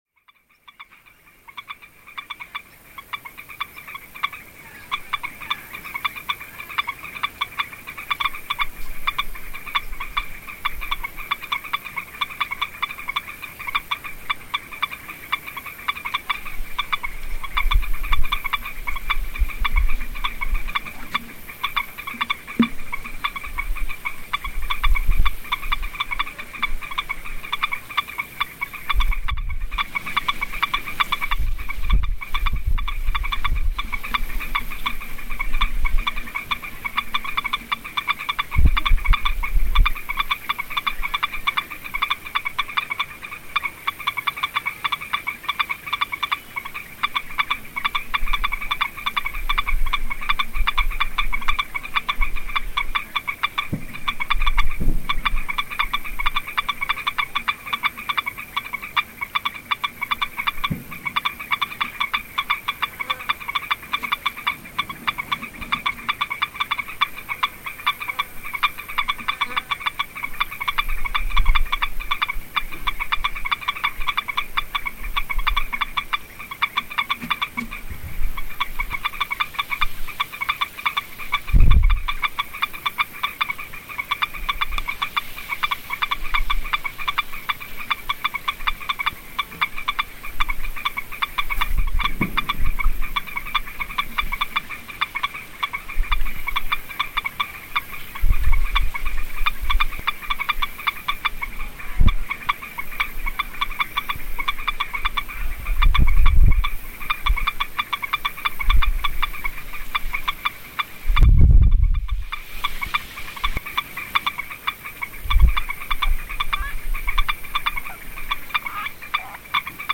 Sapos en Arroyo
Este sonido es una de las vertientes del arroyo que pasa cerca de un rancho, el puente es un pequeño trozo de madera que comunica con los papayales, la vegetación es espesa y el calor intenso.